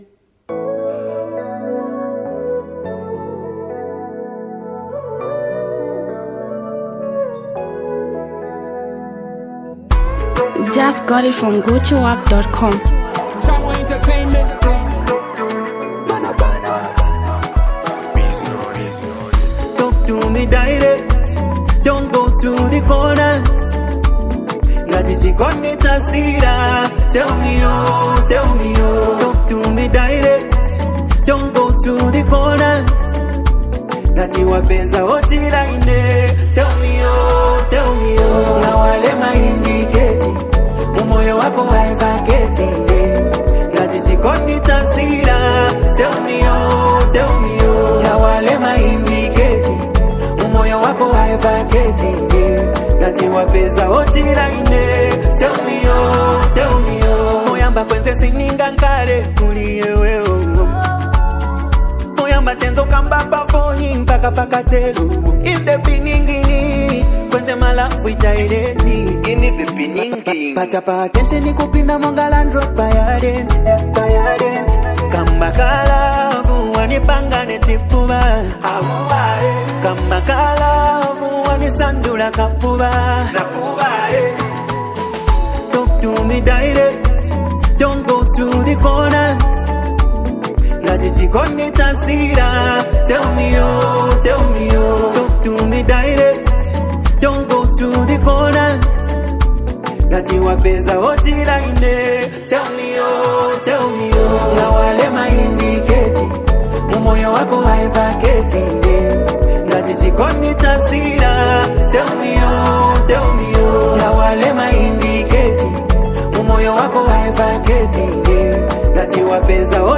Rnb’s & Afro-pop